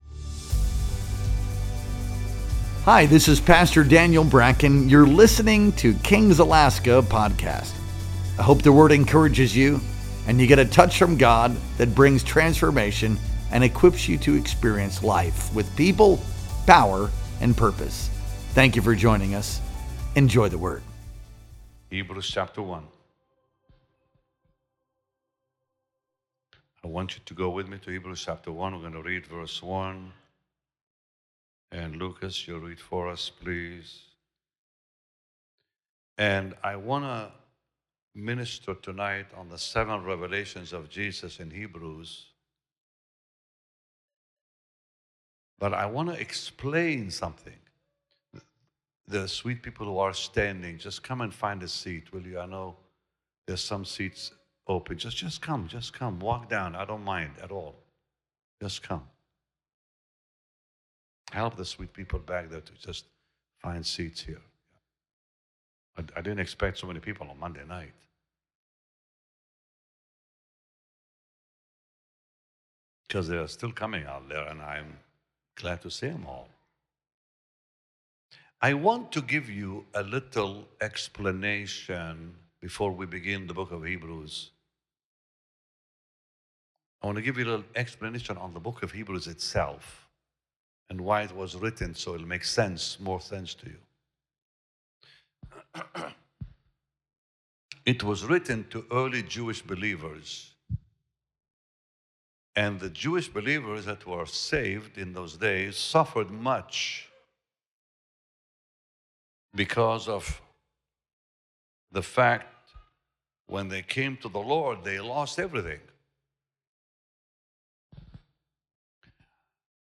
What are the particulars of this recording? Our Monday Night Worship Experience streamed live on May 19th, 2025.